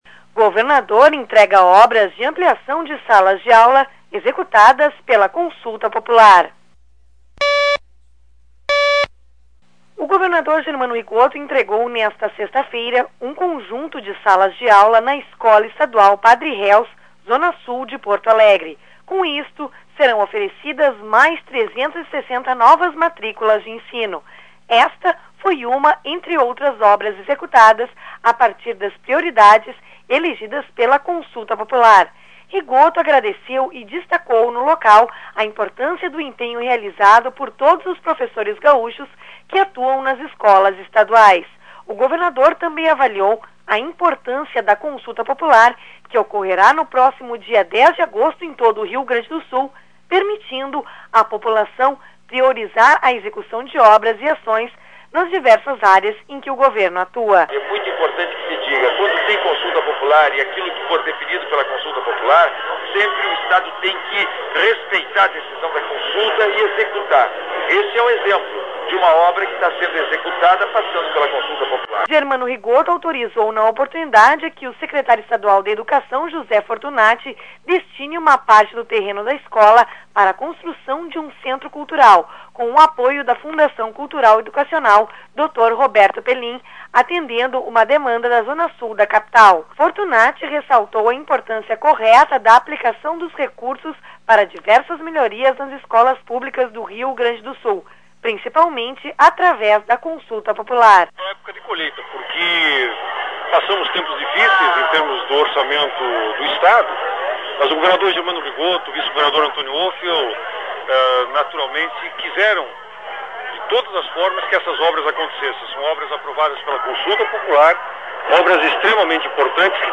O governador Germano Rigotto entregou nesta 6ª feira, um conjunto de salas de aula na Escola Estadual Padre Reus, na Zona Sul de Porto Alegre. Com isto, serão oferecidas mais 360 novas matrículas de ensino. Sonoras: governador Germano Rigotto e secretá